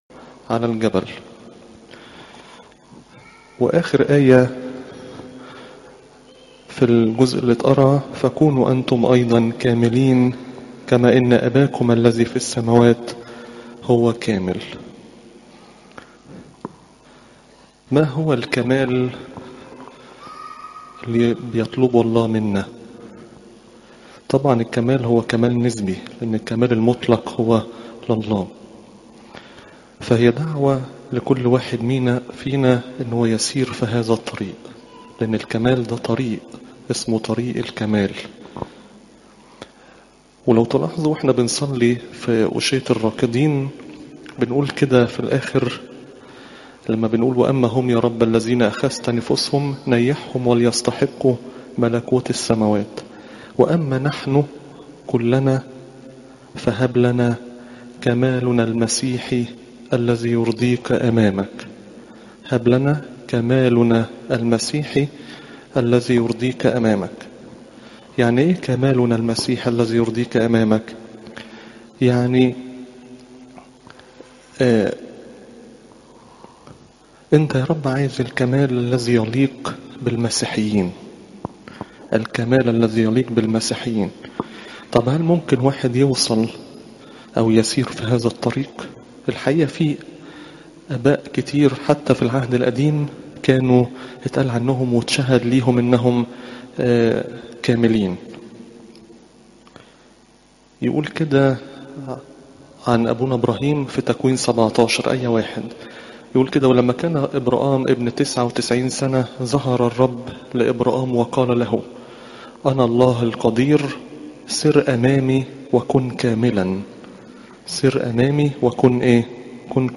عظات قداسات الكنيسة الاسبوع الاول (مت 5 : 38-48)